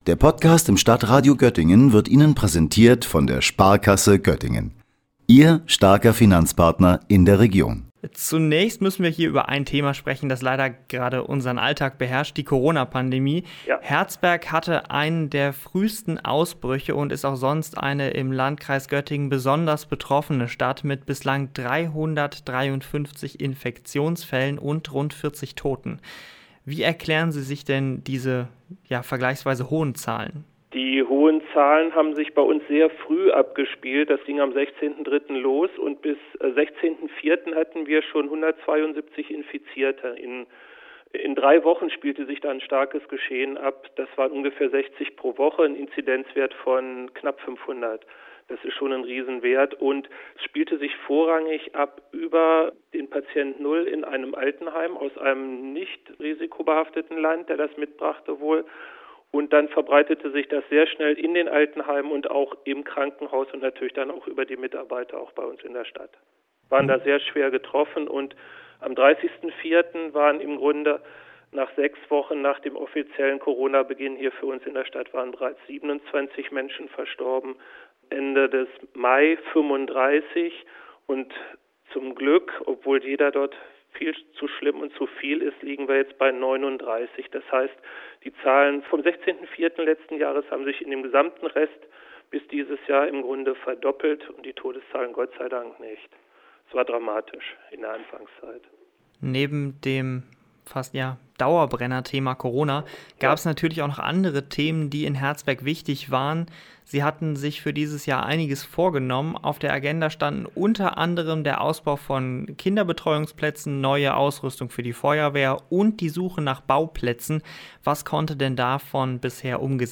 Beiträge > Corona-Pandemie und knappe Finanzen – Wie geht es weiter in Herzberg am Harz? Gespräch mit Bürgermeister Lutz Peters - StadtRadio Göttingen